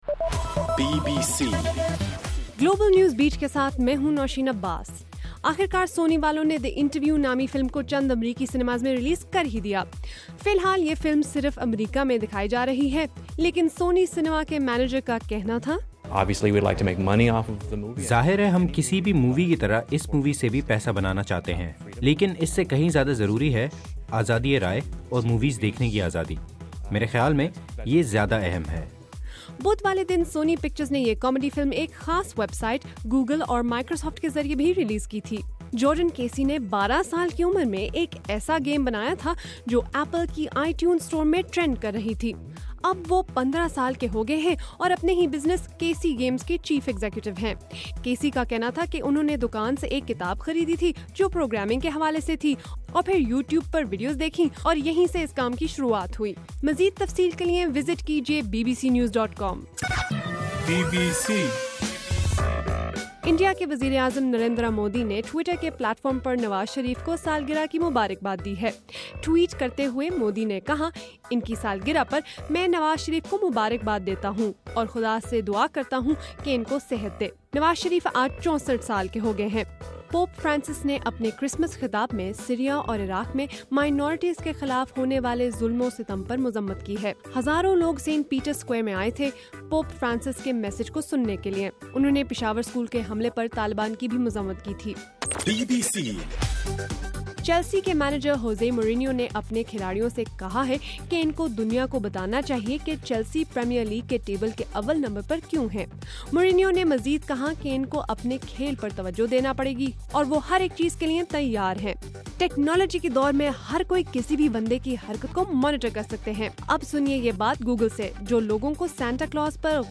دسمبر 25: رات 11 بجے کا گلوبل نیوز بیٹ بُلیٹن